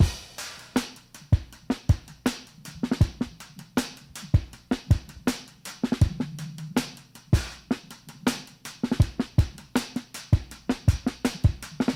street fighter break.wav